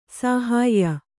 ♪ sāhāyya